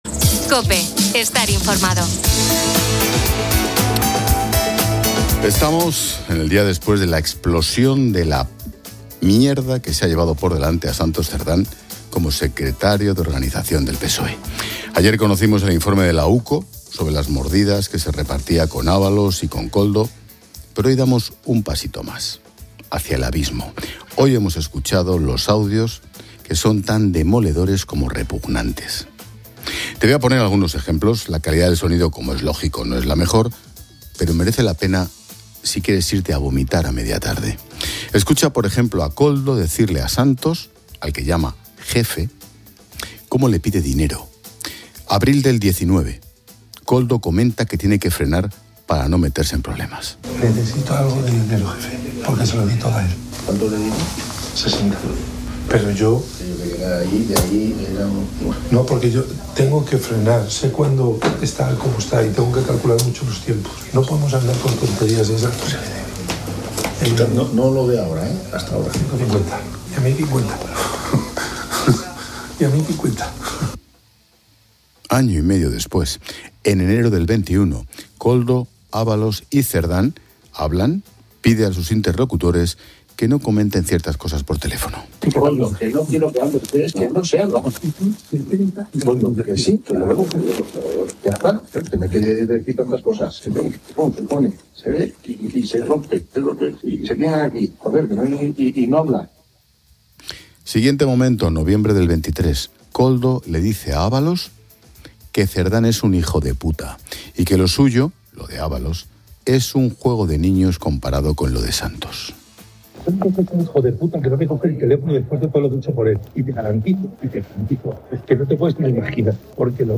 Hoy hemos escuchado los audios que son tan demoledores como repugnantes. Te voy a poner algunos ejemplos, la calidad del sonido como es lógico no es la mejor, pero merece la pena si quieres irte a vomitar a media tarde.